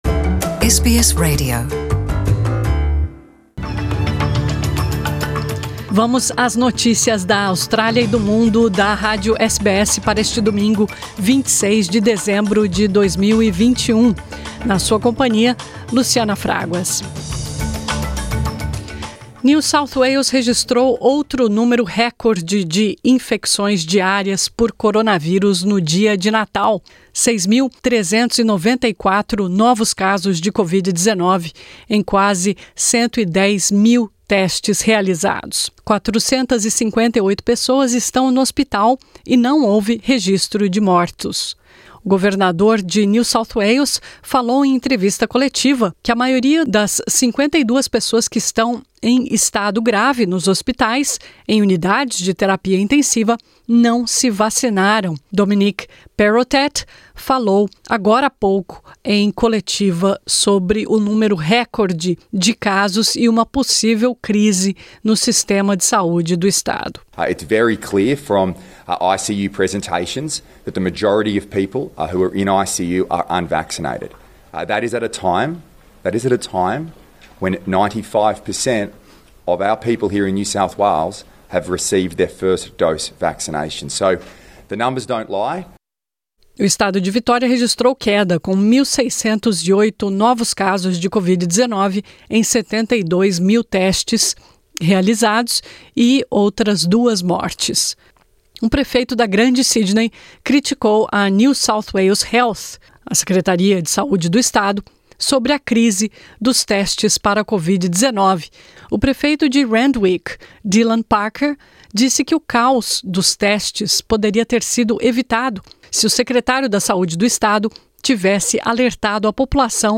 Confira as principais notícias da Austrália e do Mundo da SBS em Português para este domingo, 26 de dezembro de 2021.